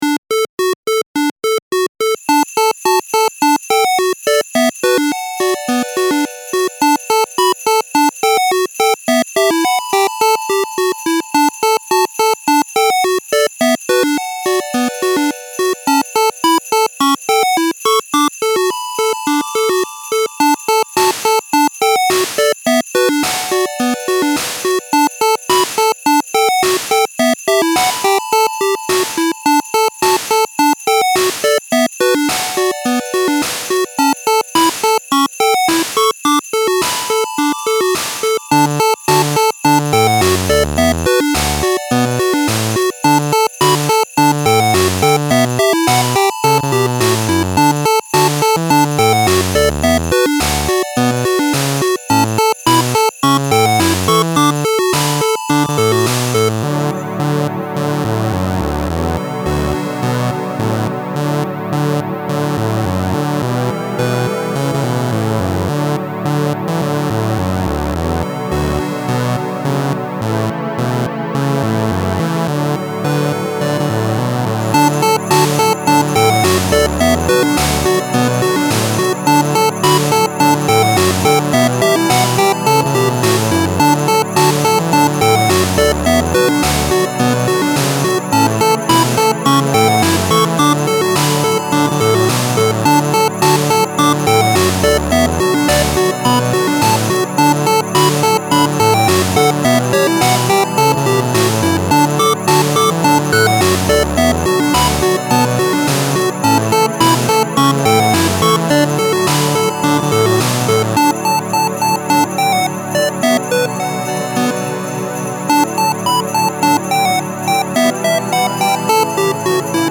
Style Style Chiptune, Seasonal
Mood Mood Bright
Featured Featured Bells, Synth
BPM BPM 106